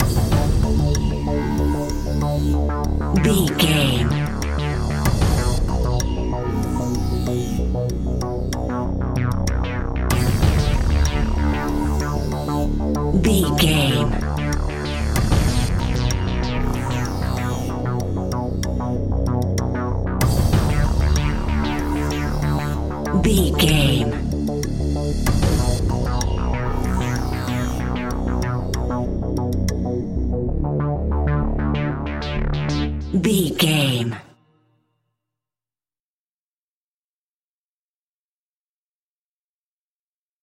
Aeolian/Minor
tension
ominous
dark
haunting
eerie
industrial
cello
synthesiser
percussion
drums
instrumentals
horror music